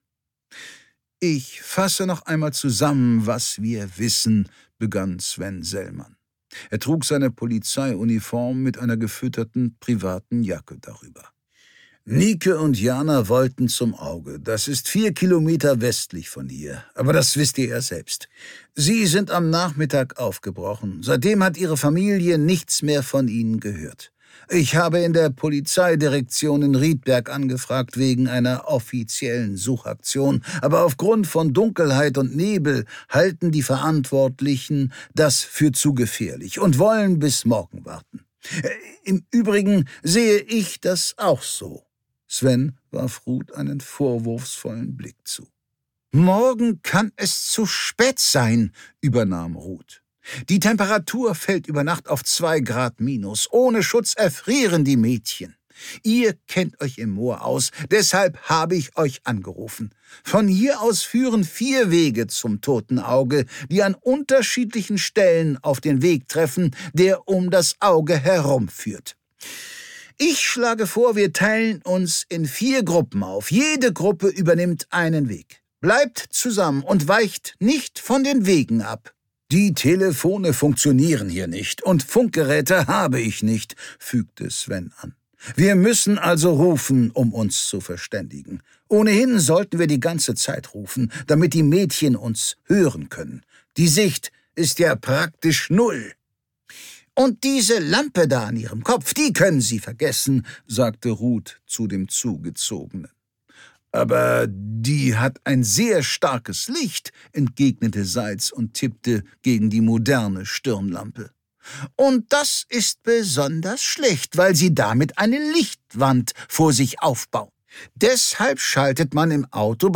Die Zwillinge - Andreas Winkelmann | argon hörbuch
Erscheinungsdatum: 11.03.2026 Andreas Winkelmann Autor Charles Rettinghaus Sprecher Bestseller Moorland.